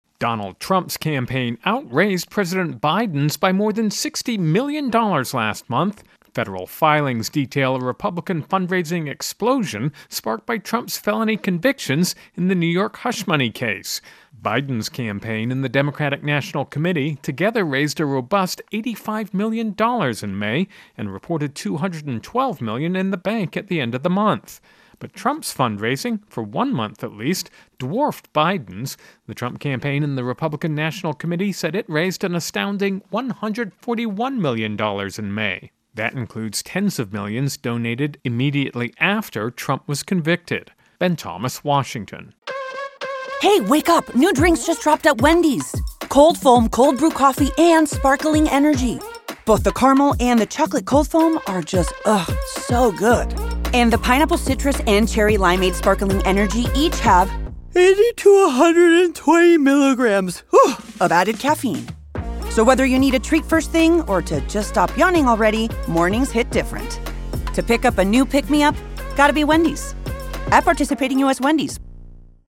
reports on the latest fundraising numbers from the presidential campaigns.